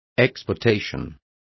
Complete with pronunciation of the translation of exportations.